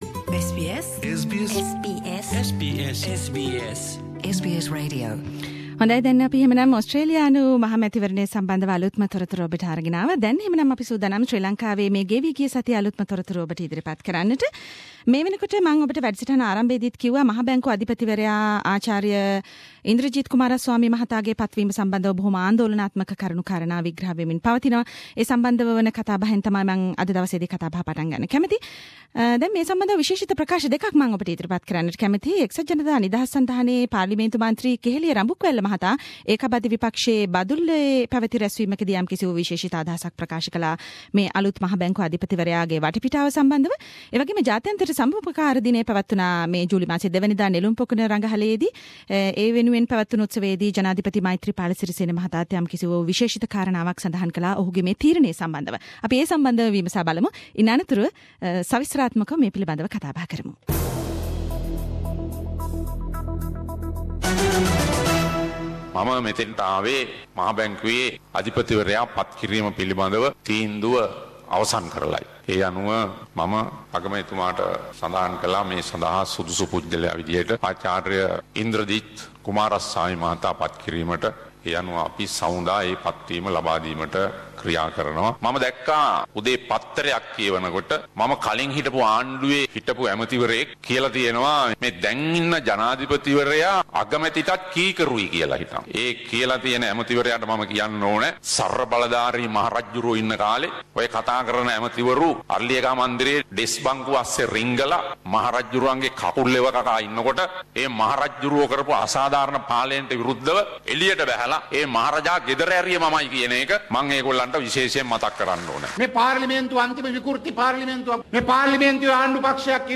Journalist - (current affair) reports from Sri Lanka